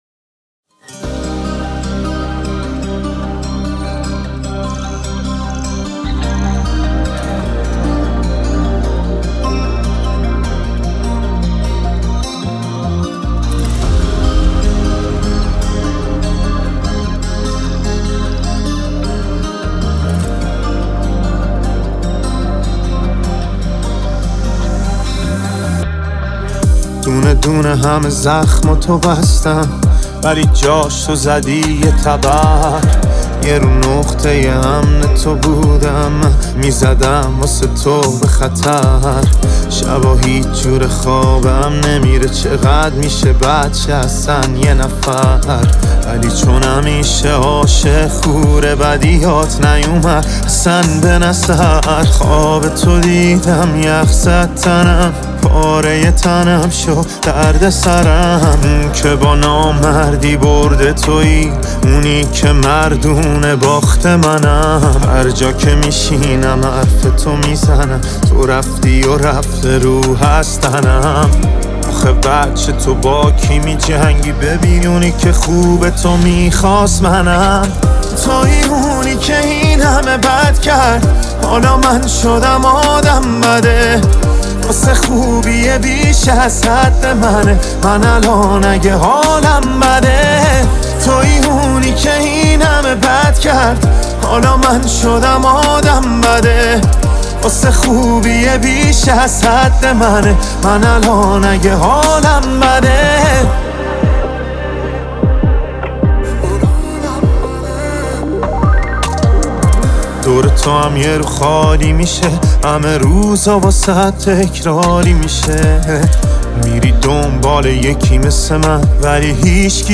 ترکیبی از ریتم‌های جدید و پرانرژی